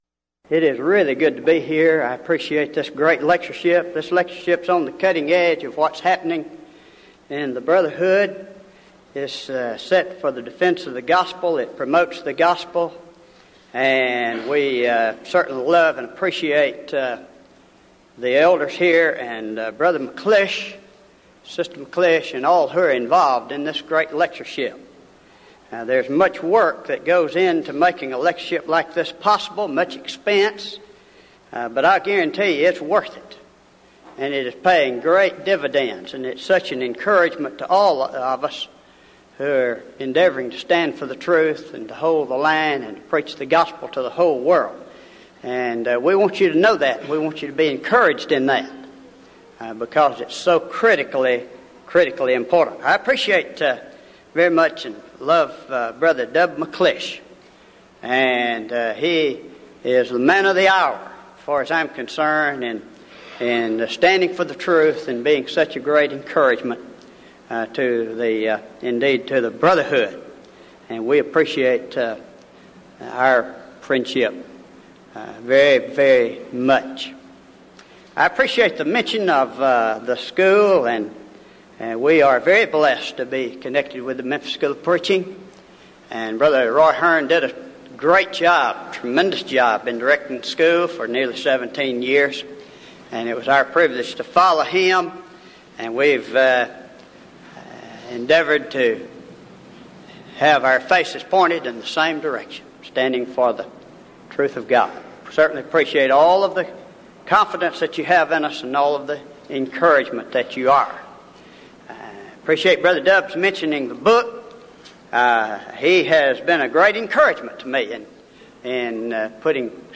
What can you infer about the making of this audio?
Event: 1998 Denton Lectures